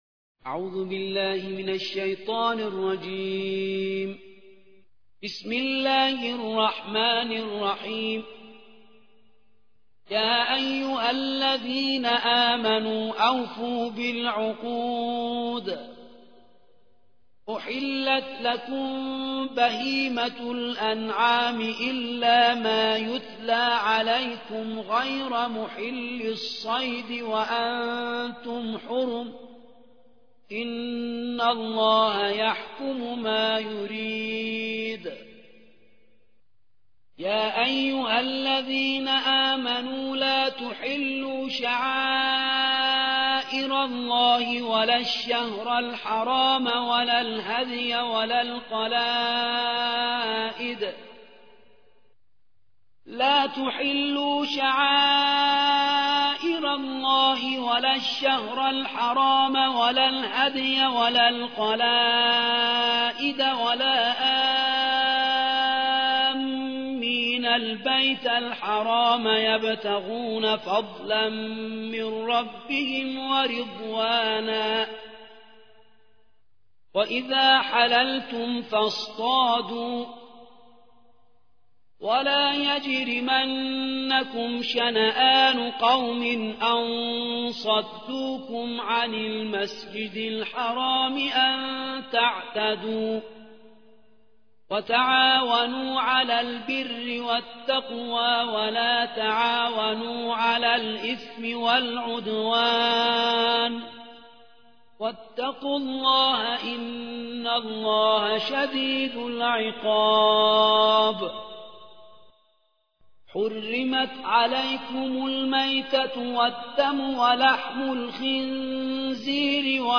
5. سورة المائدة / القارئ